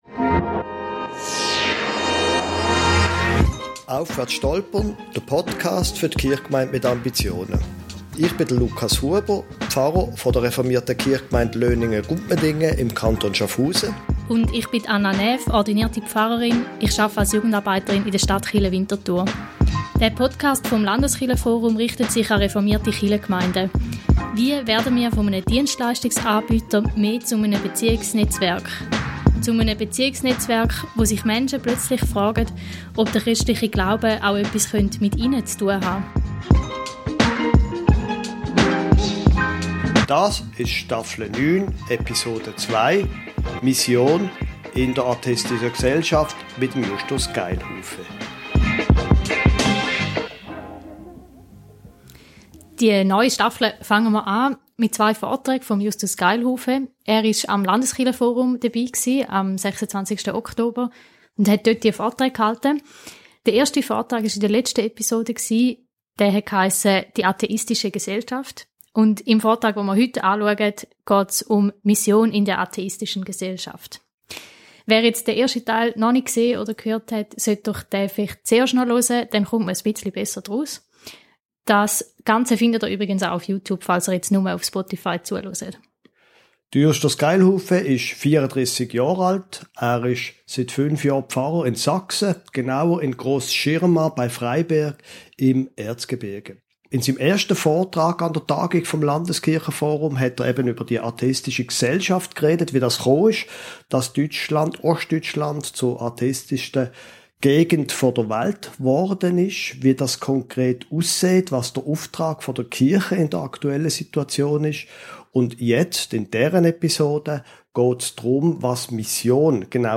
An einer Tagung des Landeskirchen-Forums hielt